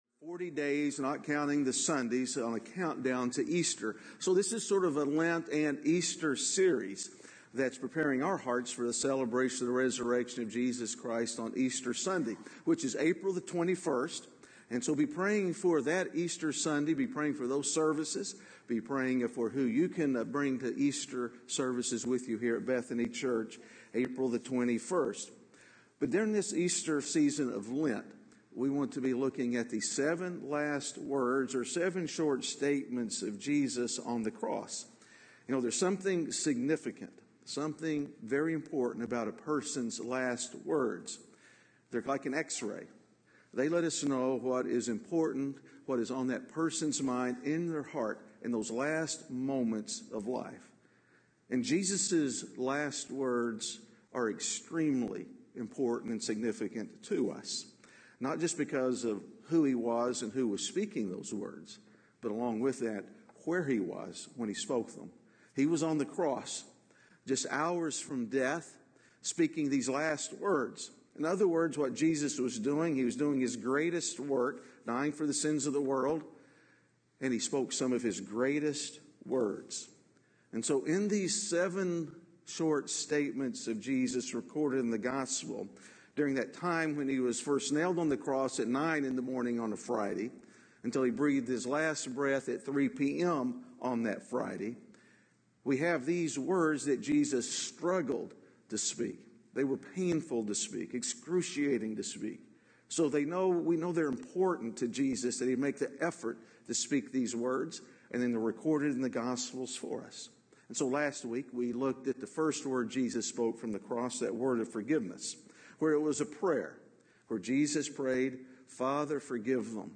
A message from the series "Parables." The parable of the good neighbor shows us what the most important thing we can do with our lives.